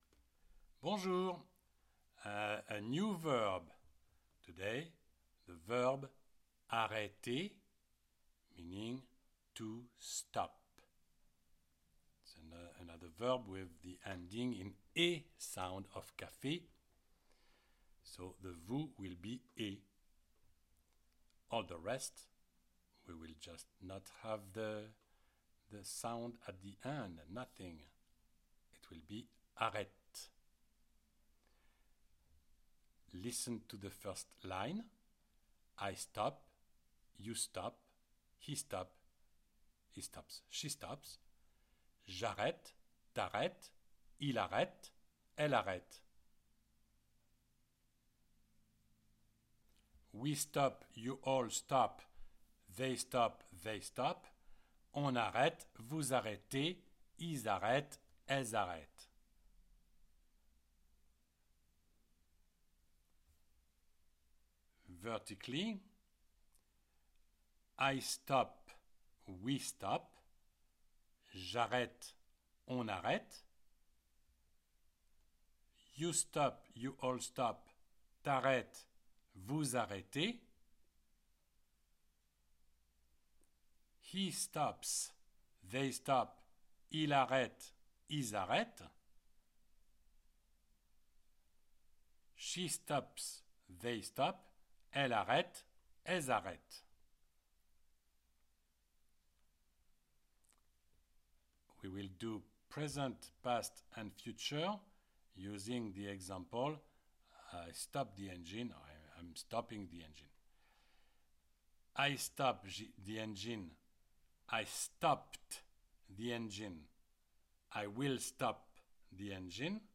CLICK ON THE PLAY BUTTON BELOW TO PRACTICE ‘ARRÊTER’, ‘TO STOP’, IN THE PRESENT TENSE Just the sight of the classic French Conjugation Chart makes it difficult to know french conversation examples .